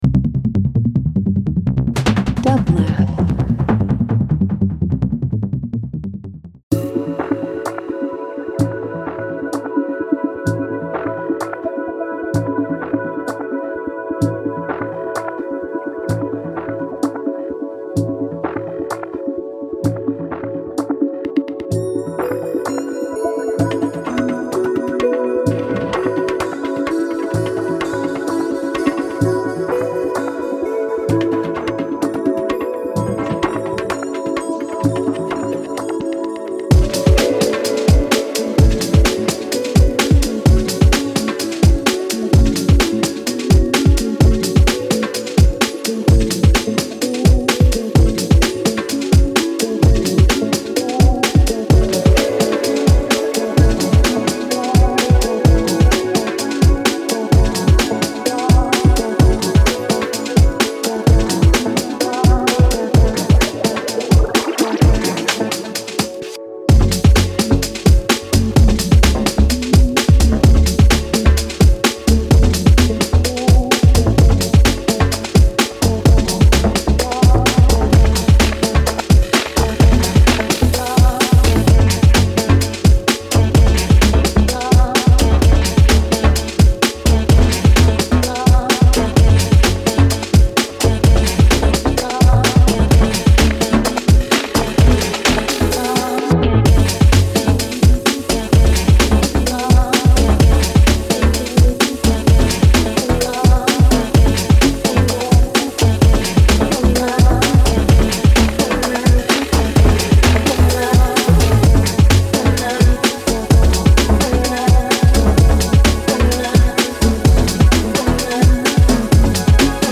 Dance Electronic House